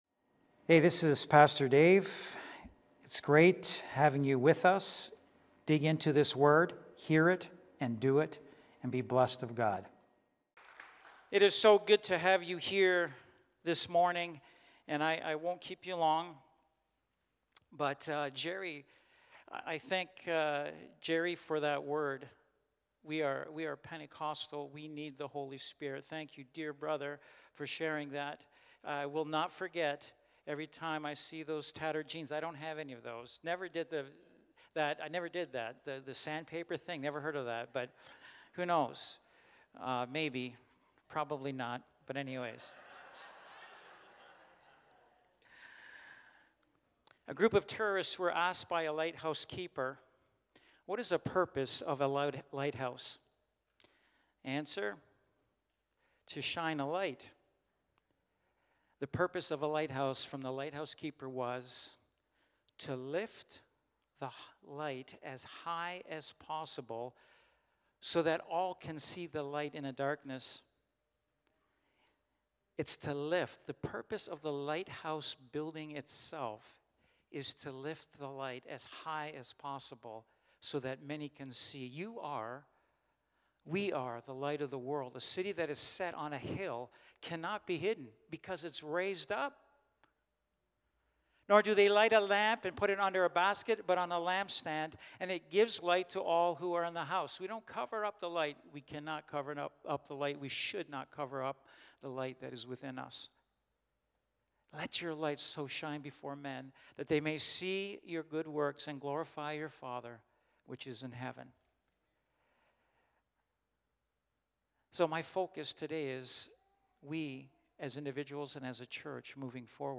Sunday Morning Service
Lighthouse Niagara Sermons